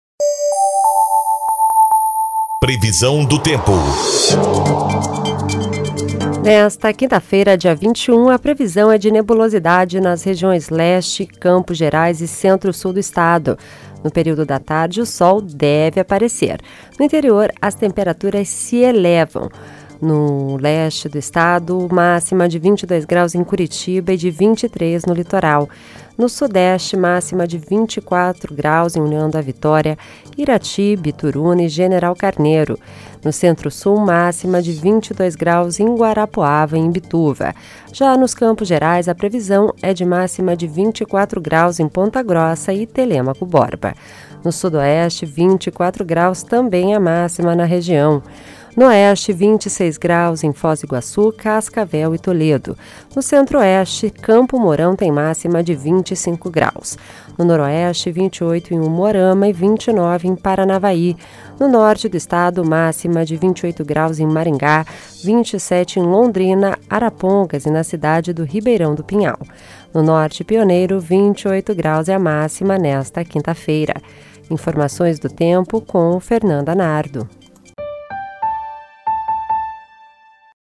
Previsão do Tempo (21/07)